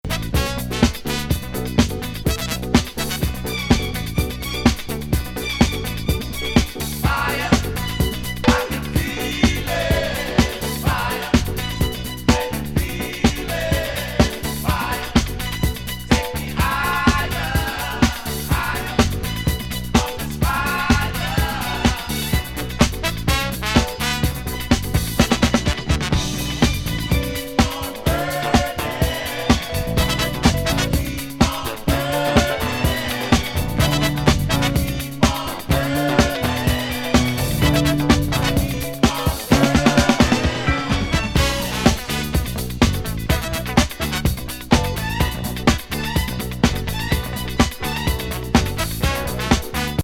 TK色ありのファンキー・ディスコ